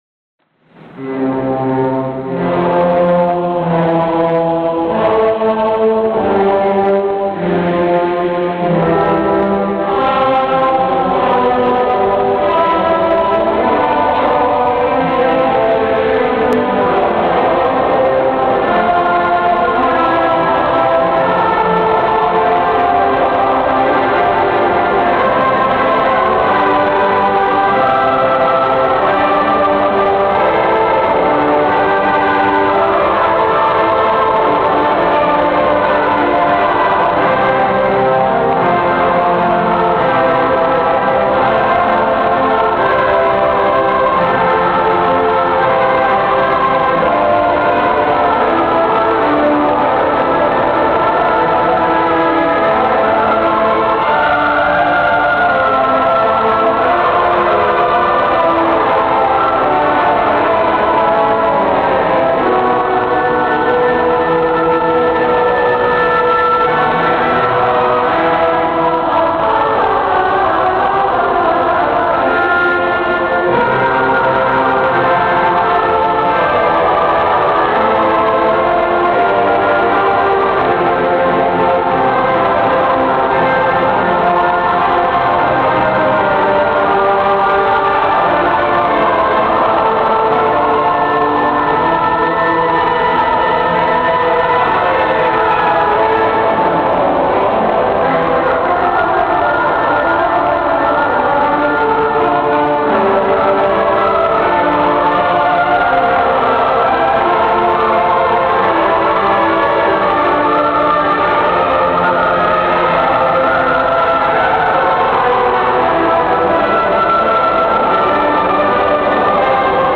Le CD du concert, le 6 octobre 2004
Eglise St-Joseph, aux Eaux-Vives à Genève
Choeur Arte Musica et
Ensemble Mare Nostrum
Retour en arrière Vers le haut de la page  (enregistrement amateur)